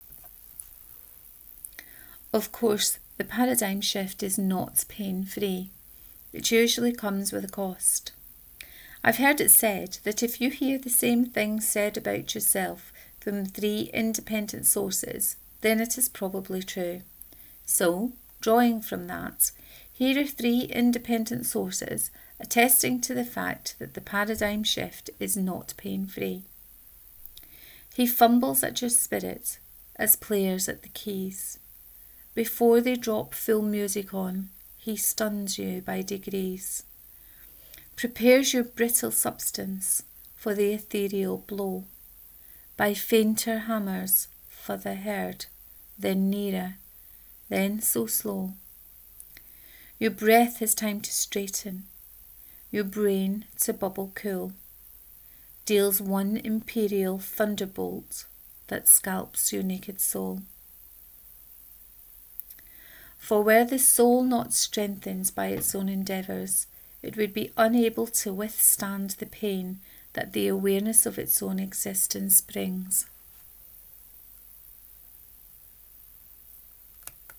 The Paradigm Shift 3 : Reading of this post